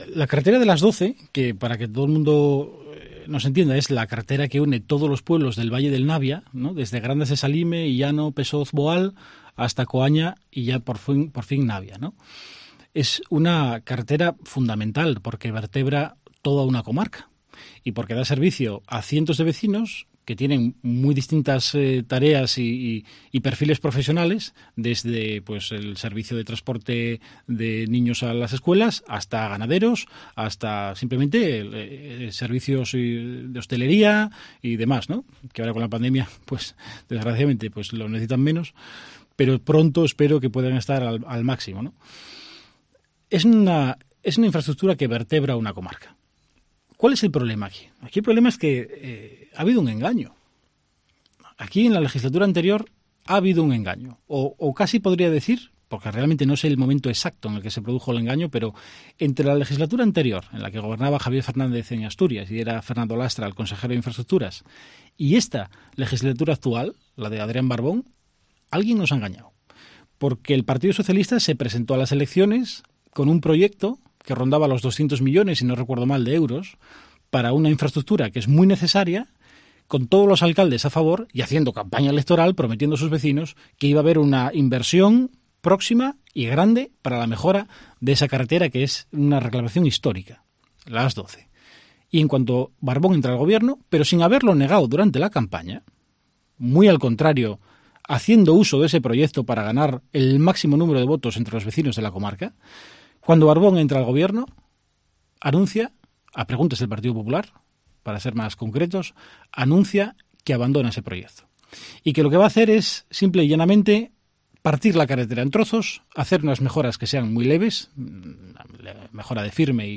Declaraciones del diputado por el Occidente Astur, Álvaro Queipo, sobre la AS-12